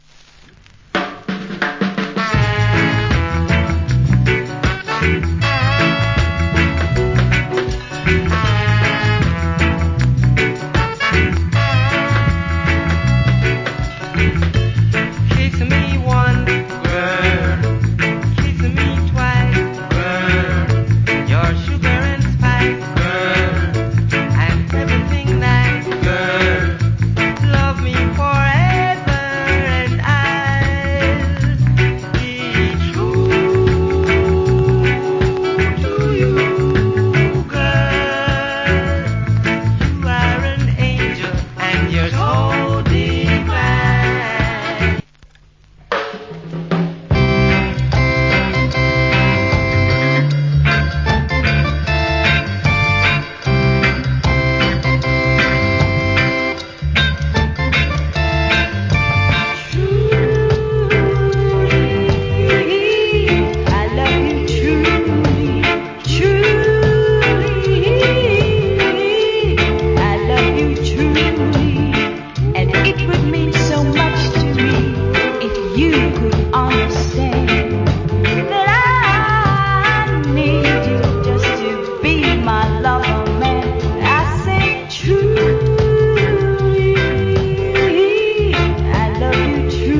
Foundation Rock Steady.